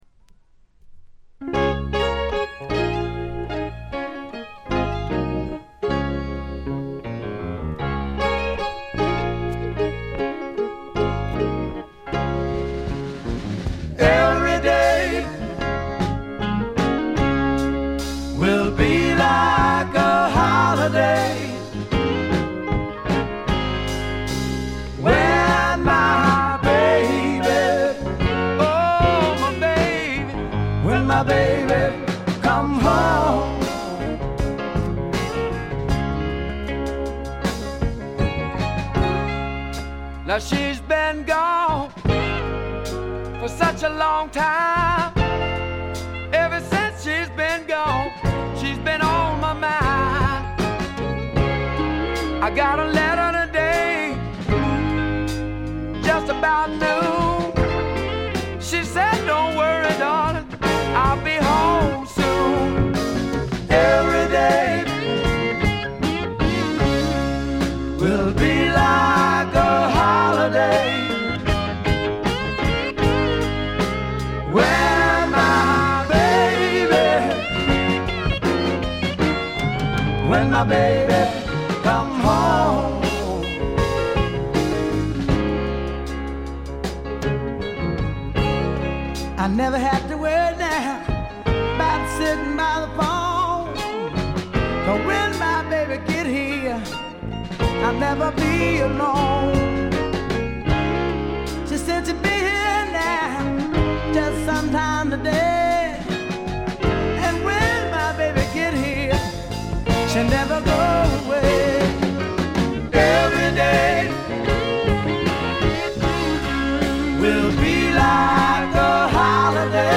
部分試聴ですがほとんどノイズ感無し。
びしっと決まった硬派なスワンプ・ロックを聴かせます。
試聴曲は現品からの取り込み音源です。
Recorded at Paramount Recording Studio.